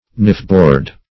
Knifeboard \Knife"board`\, n. A board on which knives are cleaned or polished.